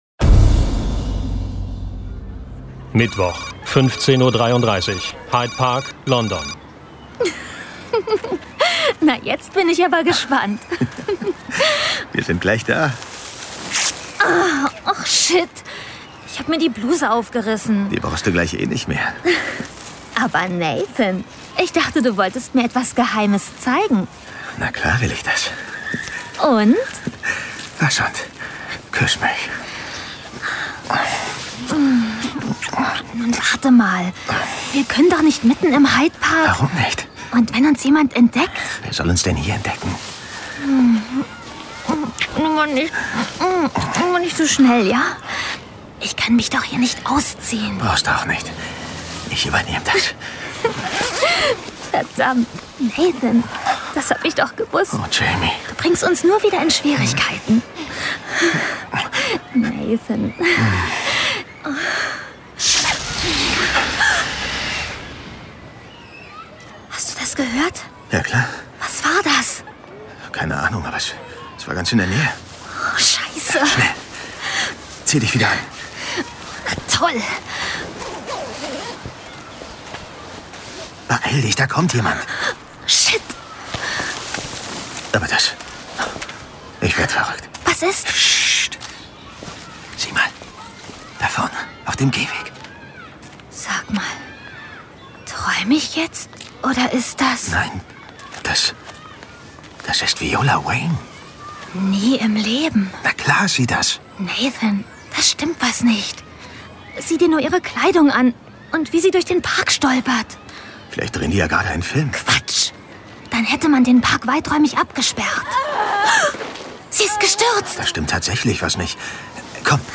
John Sinclair Classics - Folge 2 Mörder aus dem Totenreich. Hörspiel.